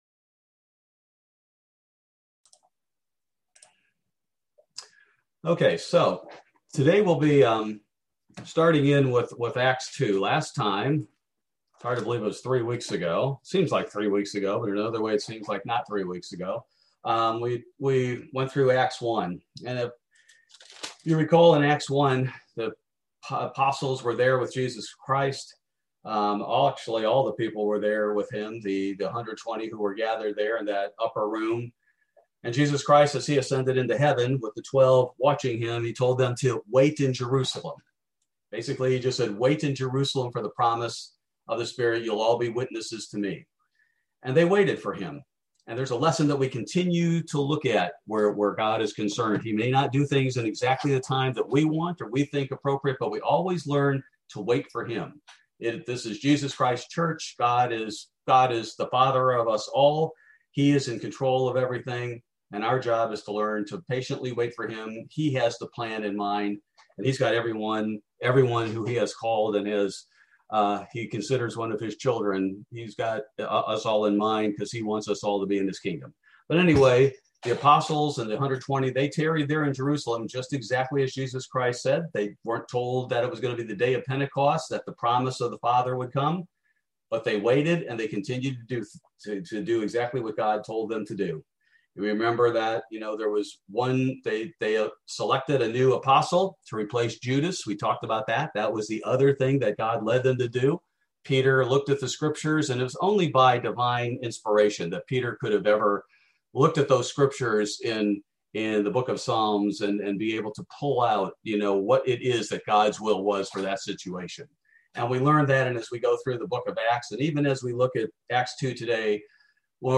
Bible Study: May 12, 2021